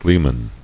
[glee·man]